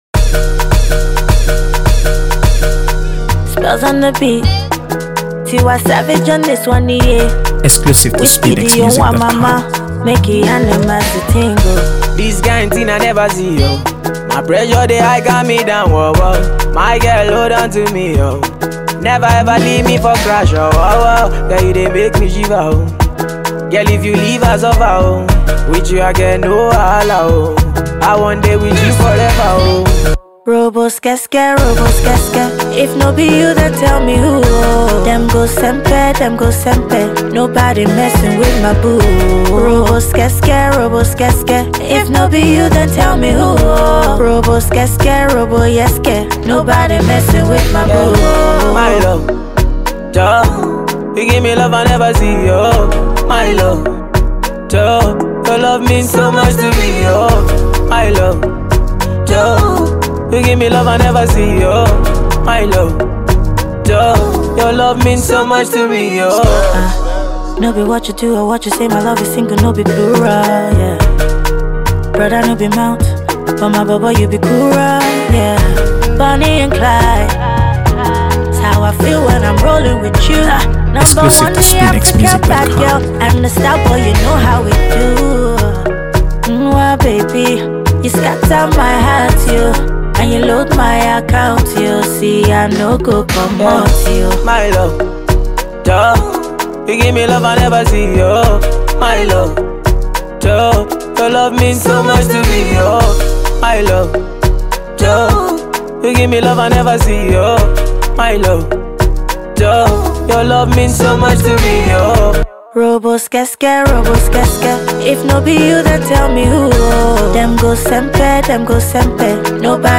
AfroBeats | AfroBeats songs
With its smooth blend of Afrobeats and R&B vibes